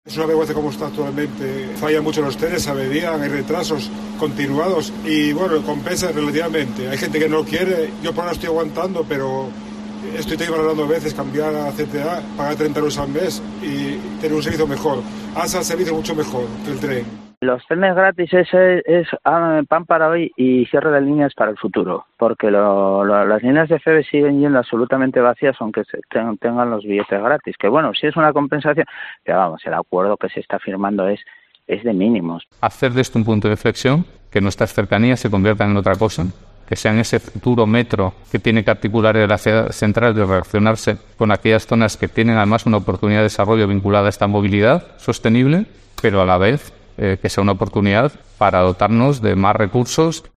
Usuarios del tren y Alejandro Calvo sobre el acuerdo de Madrid